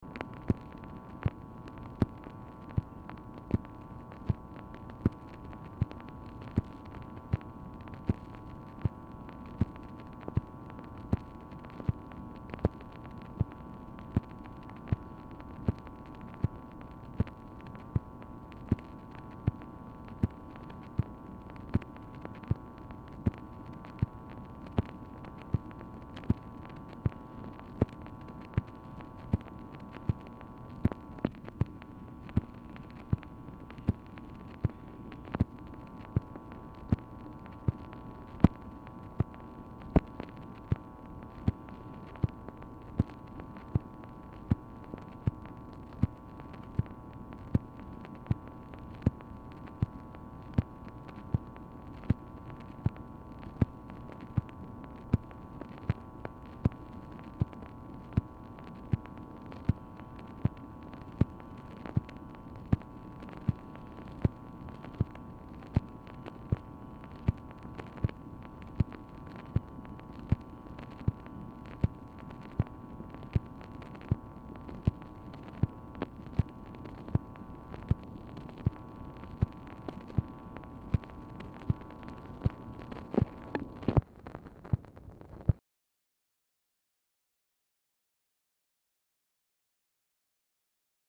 Telephone conversation # 9302, sound recording, MACHINE NOISE, 12/1/1965, time unknown | Discover LBJ
Telephone conversation
Dictation belt
LBJ Ranch, near Stonewall, Texas